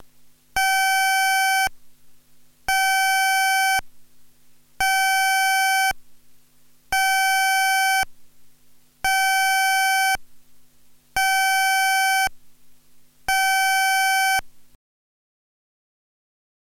Sirena electrónica antideflagrante
32 sonidos, 109 dB.